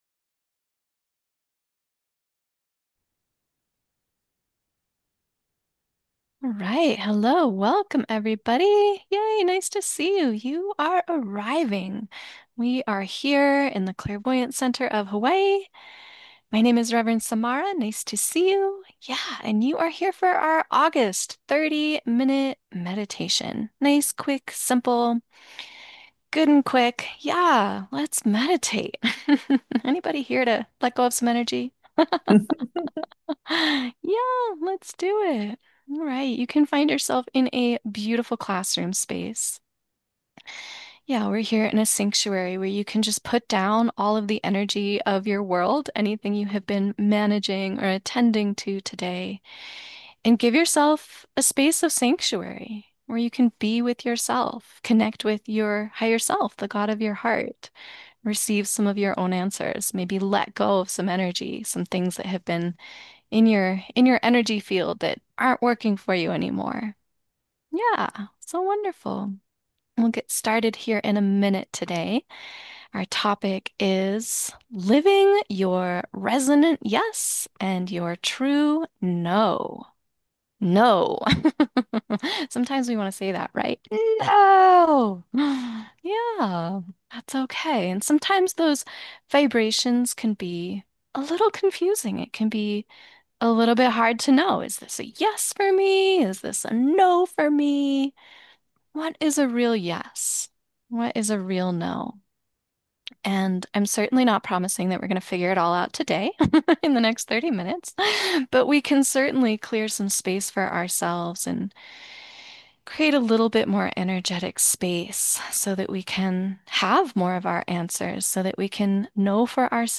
August-2025-Meditation-Recording.mp3